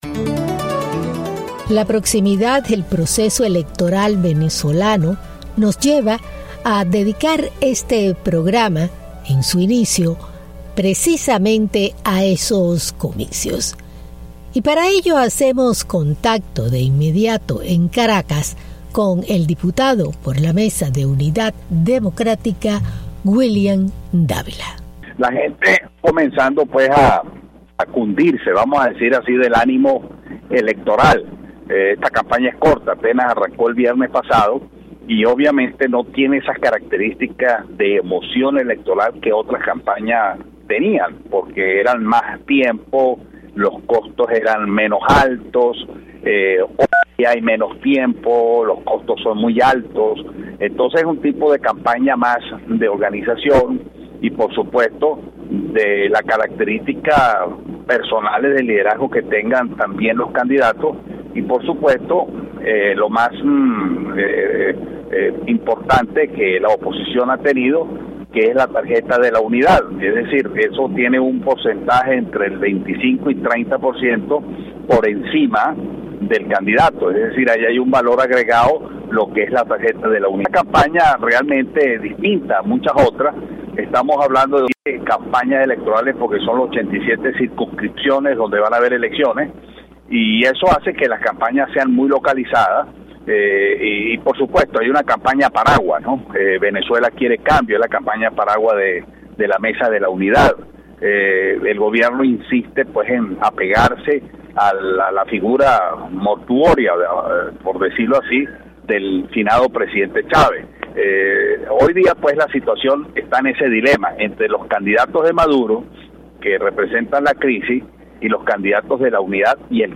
Sobre proximas elecciones parlamentarias en Venezuela, hablamos con el diputado Wuilliam Davila. Ademas, vuelven a posponer el juicio al alcalde de Caracas Antonio Ledesma.